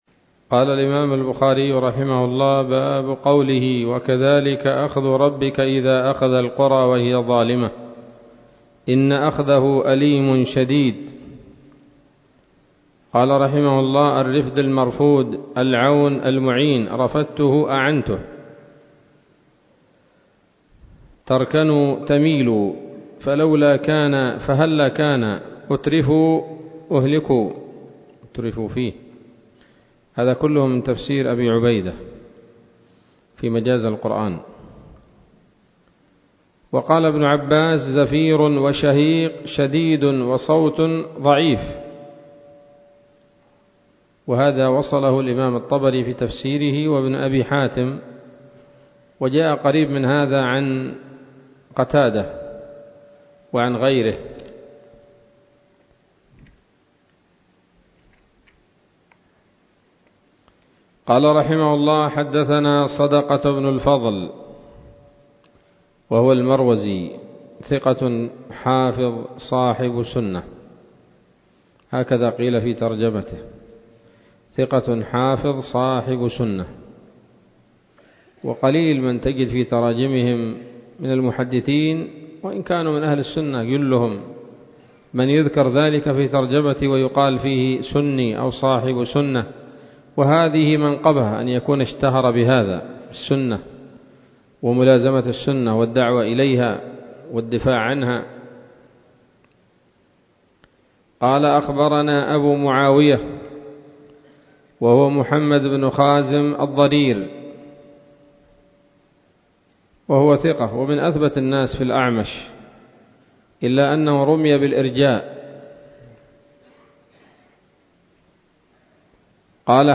الدرس السابع والثلاثون بعد المائة من كتاب التفسير من صحيح الإمام البخاري